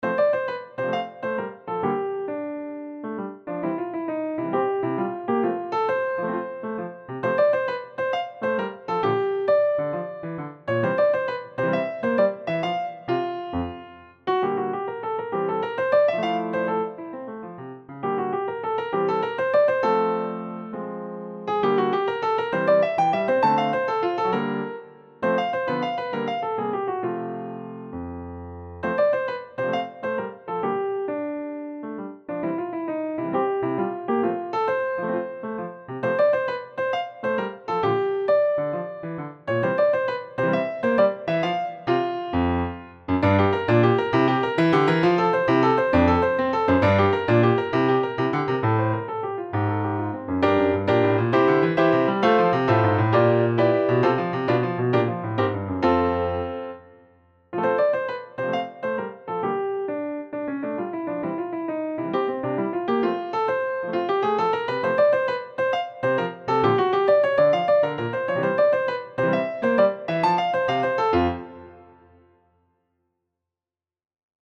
Dance of the Little Yellow Pixies Solo piano, 1:14.
default piano